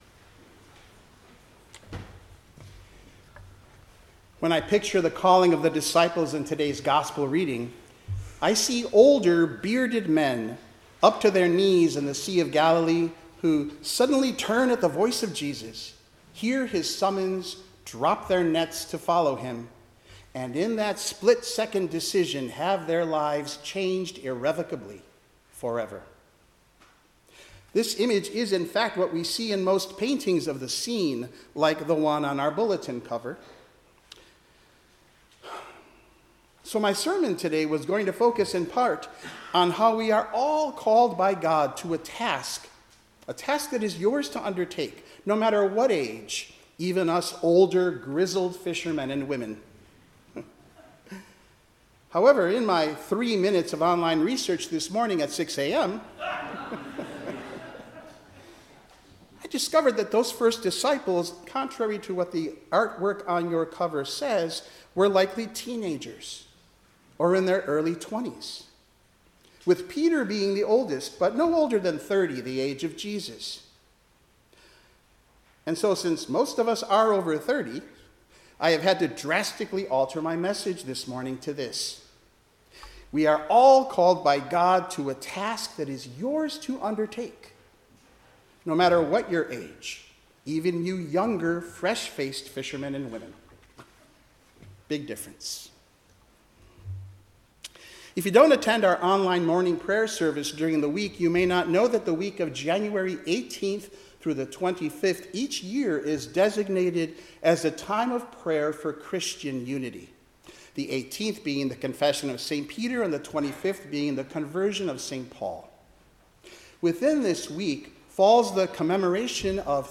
Passage: Isaiah 9:1-4, Psalm 27:1,5-13, 1 Corinthians 1:10-18, Matthew 4:12-23 Service Type: 10:00 am Service